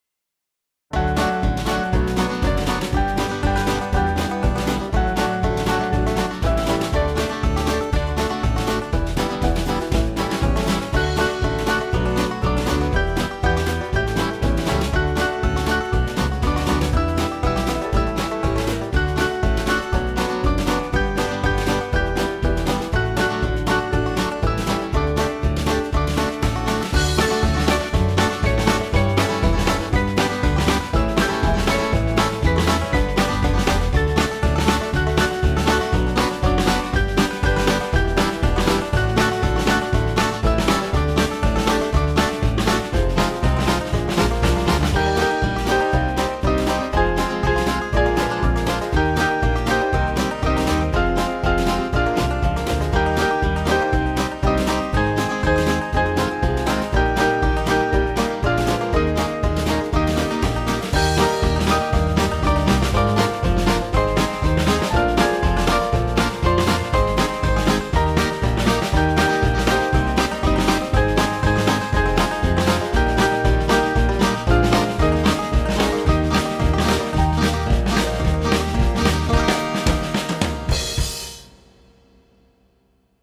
Small Band
With Banjo   428.8kb